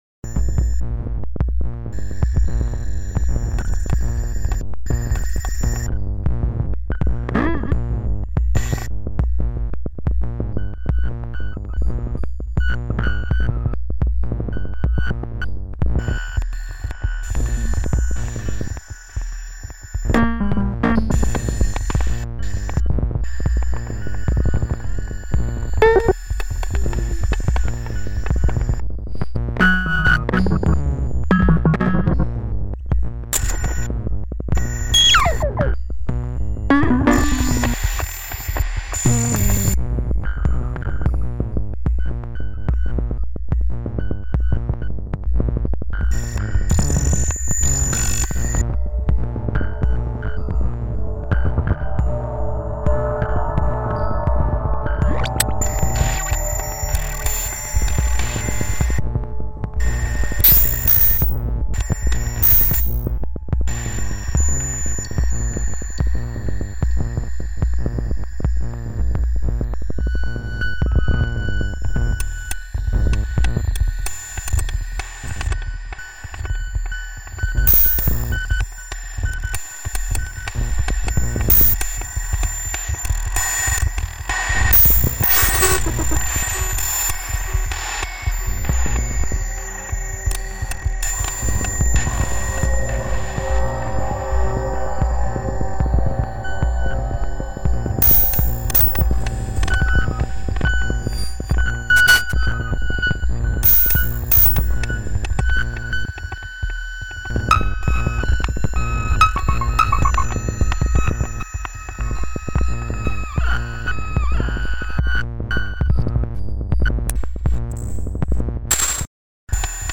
Os sons têm carácter, não coexistem como mera amálgama.